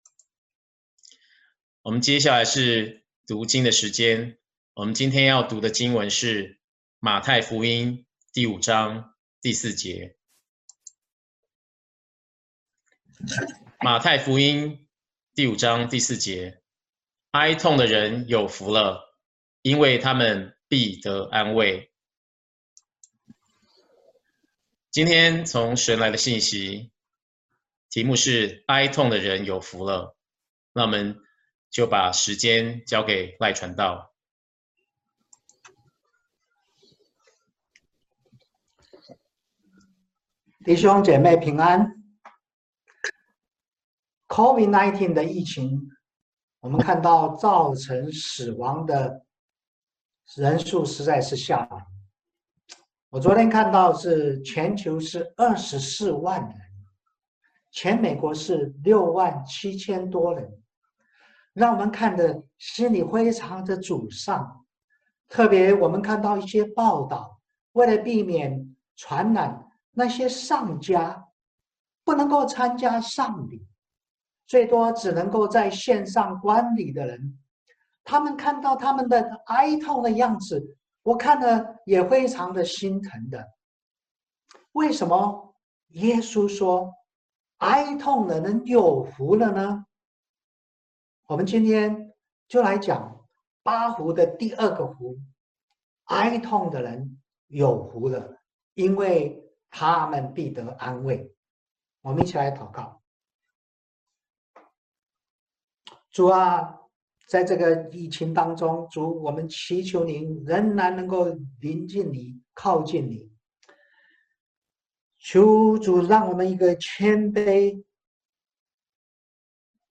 Bible Text: 馬太 5:4 | Preacher